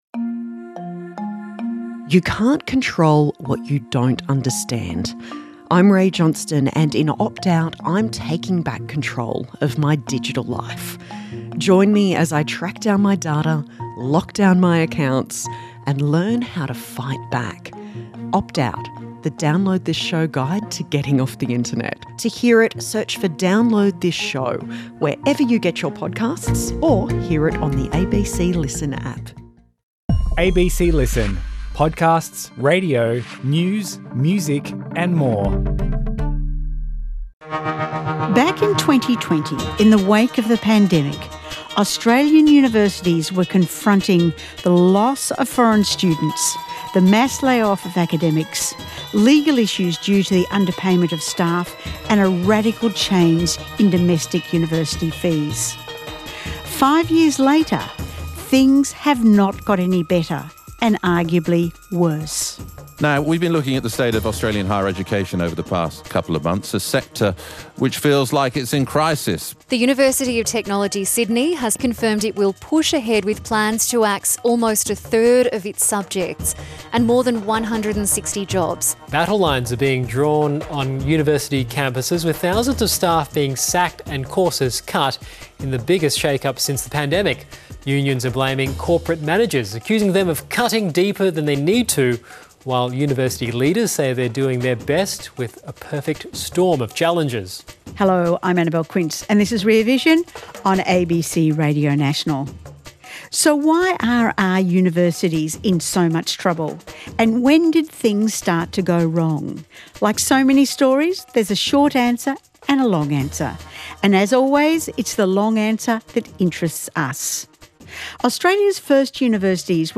Recording on Tuesday (Day One) of Web Summit 2025